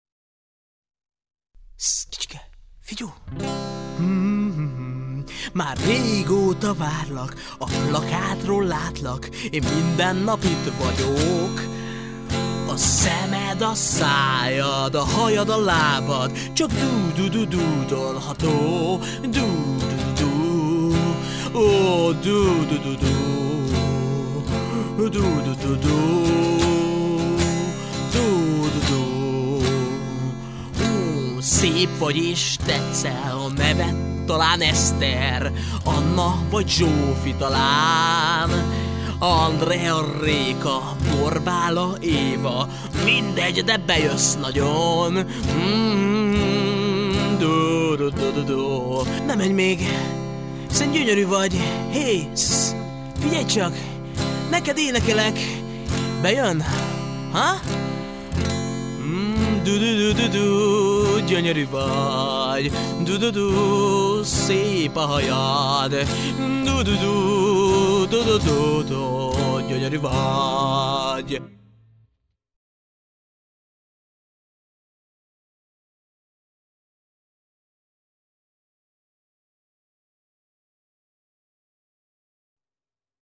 Lírai zene, 2 MB